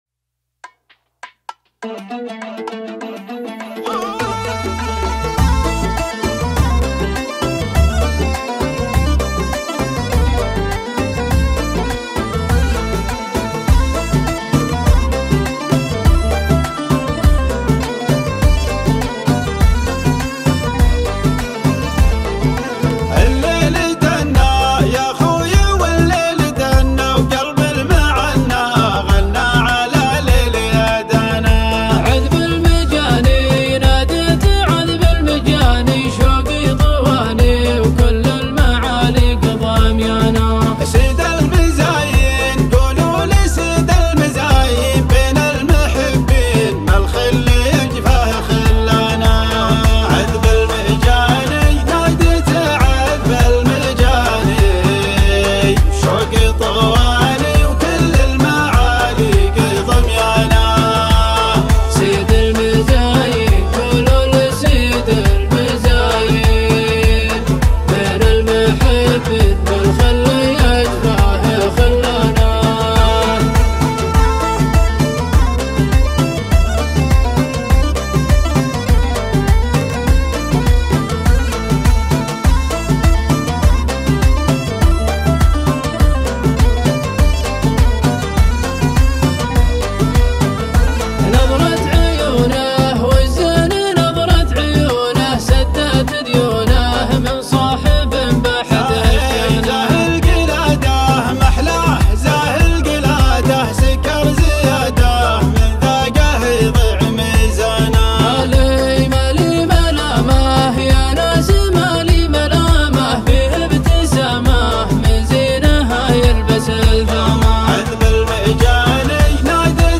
شيلات دويتو